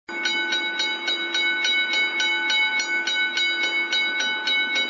4 – نغمة صوت صفارة وصول القطار